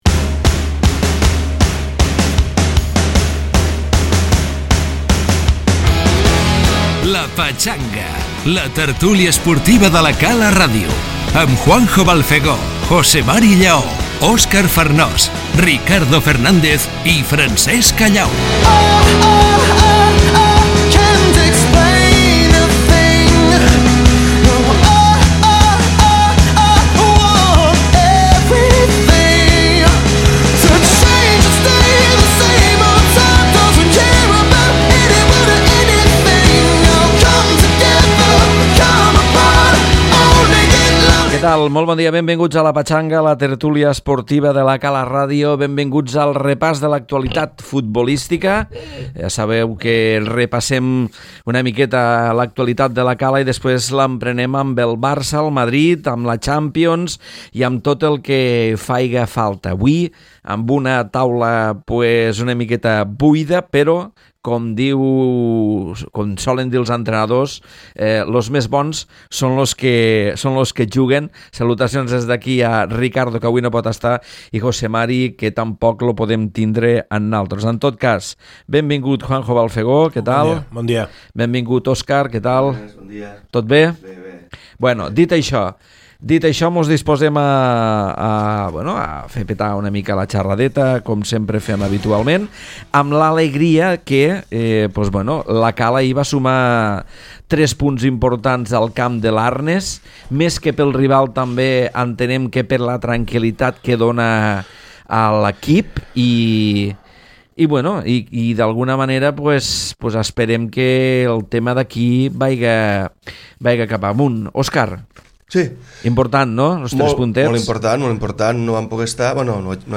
Els tres punts de La Cala, els tres del Madrid, i l’un del Barça analitzats a la tertúlia futbolística dels dilluns.